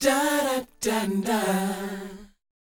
DOWOP D 4B.wav